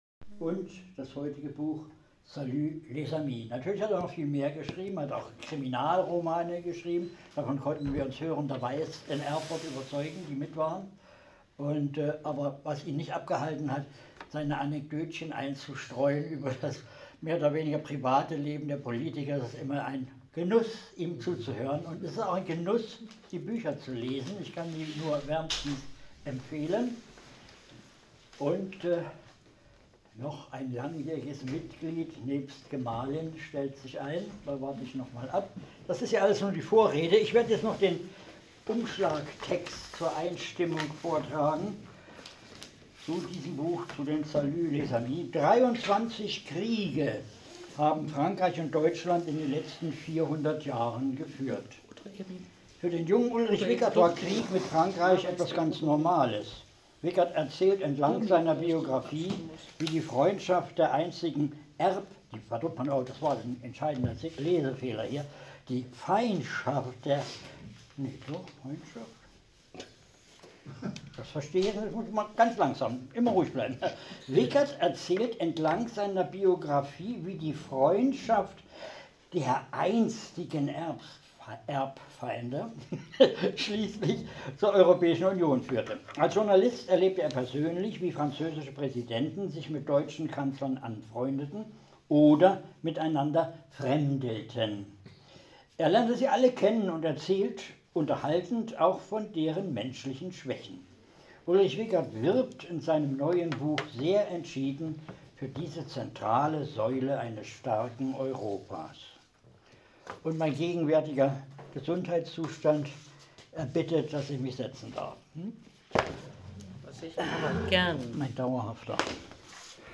Wickert schreibt mit ansteckend guter Laune in seinem Buch verblüffende Geschichten der deutsch-französischen Beziehungen mit vielen internen Begebenheiten, wie beispielsweise Helmut Schmidt bei einem Treffen mit Valéry Giscard d'Estaing den ihm angebotenen Rotwein ablehnte und -Café mit Drarambuie- (einem hochprozentigen Whisky ) vorzog.. Ein sehr gelungener Abend und alle Teilnehmer spendeten am Schluß Beifall.